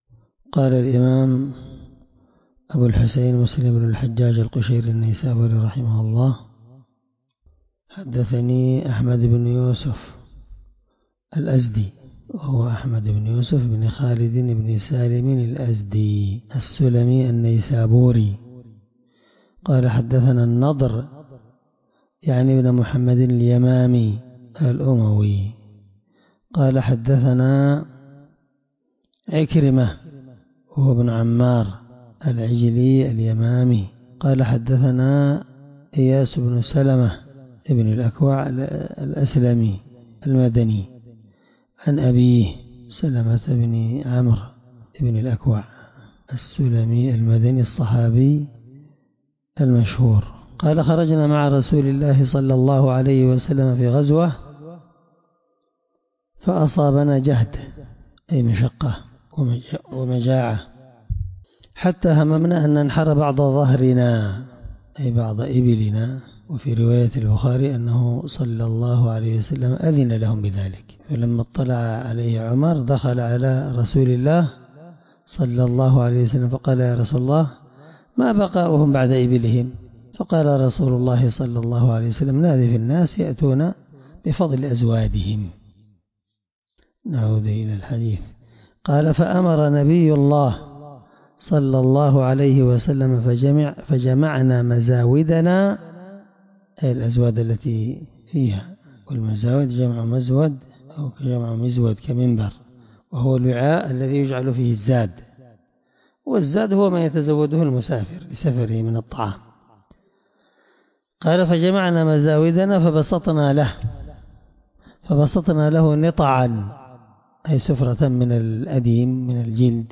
الدرس2من شرح كتاب المغازي حديث رقم(1729) من صحيح مسلم